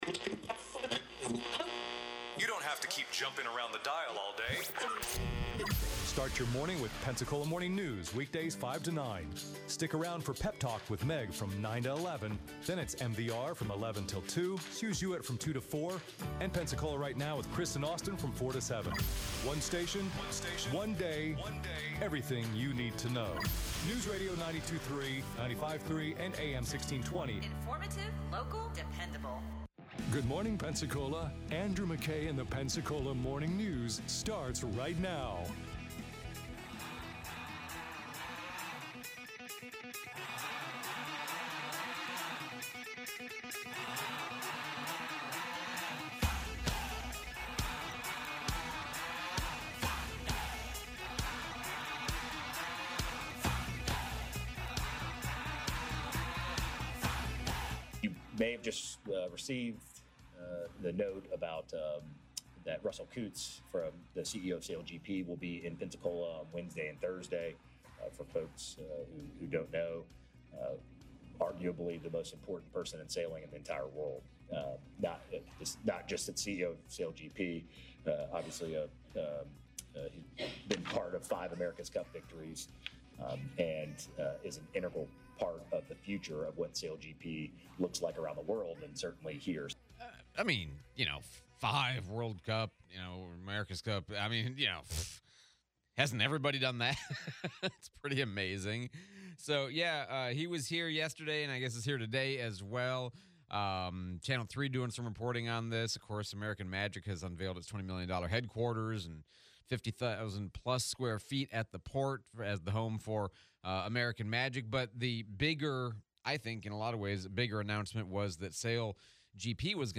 American Magic update, Bay Center plans, Mayor Reeves interview replay